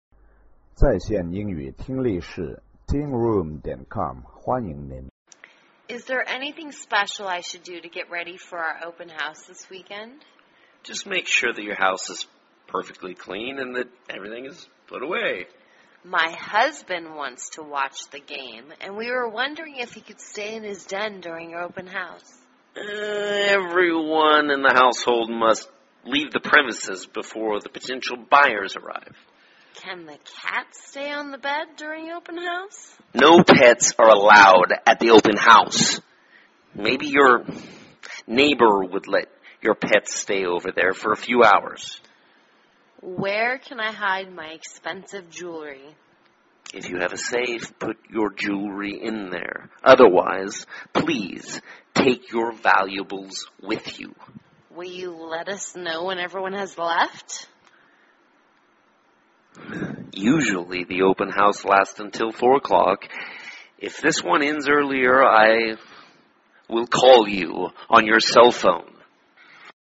卖房英语对话-Preparing for an Open House(2) 听力文件下载—在线英语听力室